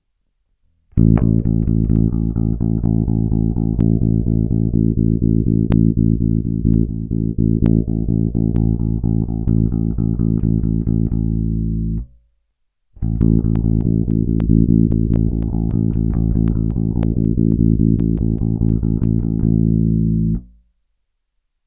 Dalo by se říct, že filtr dělá jakoby wah-wah efekt. V poslední ukázce jsem měnil rychle přepínač filtru, nejprve po 4 tónech jednou tam a zpátky, pak velmi rychle tam a zpět dvakrát. Omluvám se za drobné prskance vzniklé při přepnutí, nedokázal jsem je ze zvuku vypreparovat.